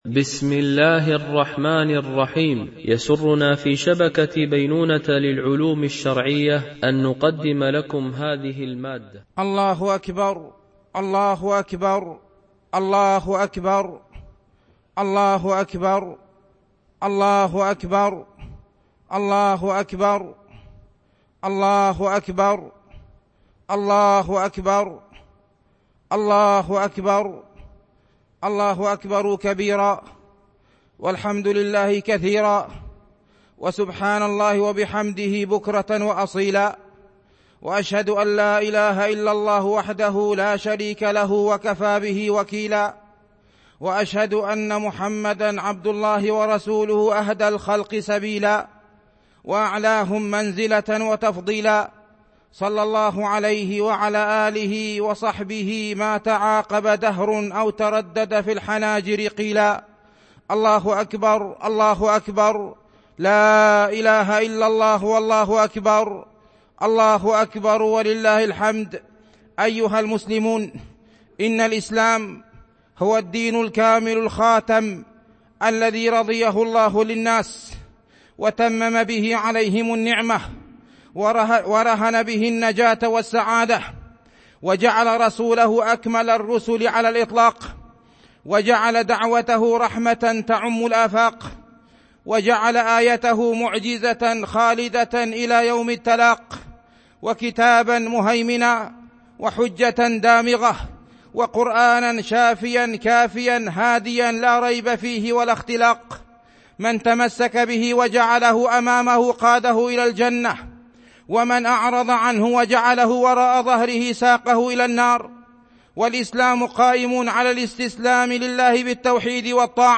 خطبة عيد الفطر لعام ١٤٤٢ هـ